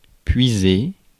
Ääntäminen
IPA: /pɥi.ze/